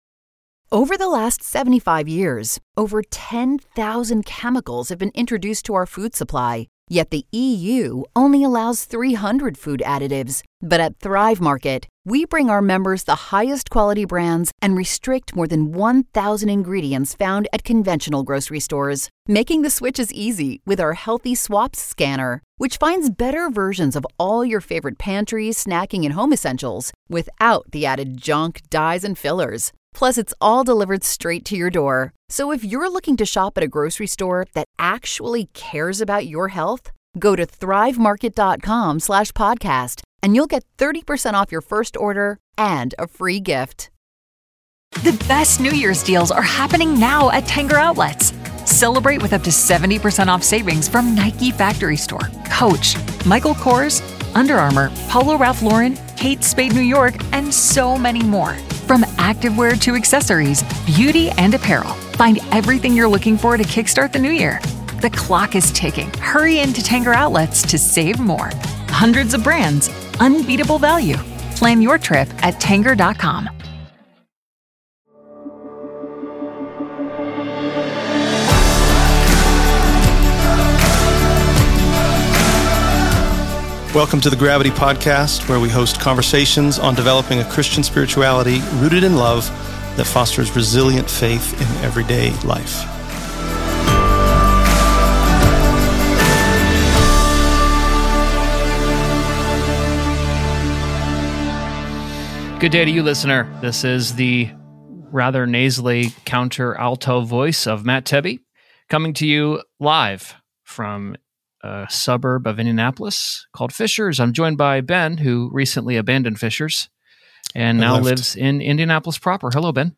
Our conversation covers topics like how we read the Bible, our inherited traditions, accountability,…